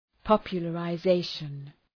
Προφορά
{,pɒpjələrə’zeıʃən}